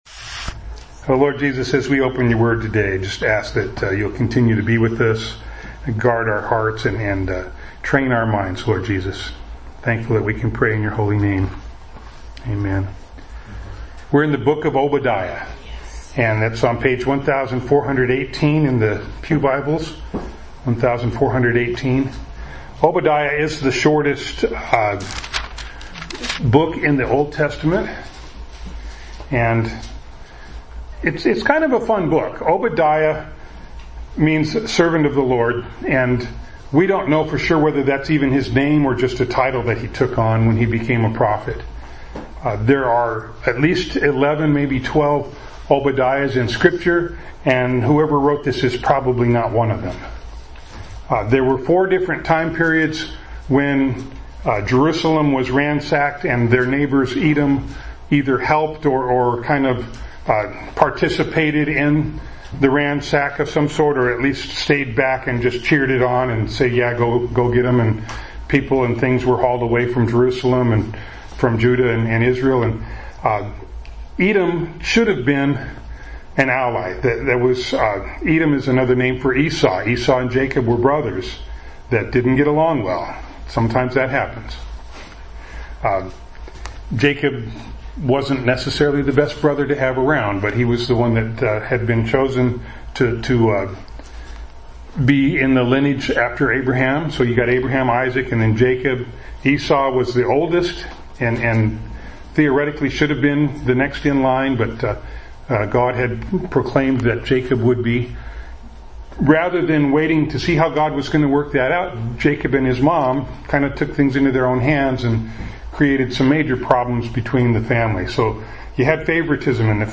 Bible Text: Obadiah 1-21 | Preacher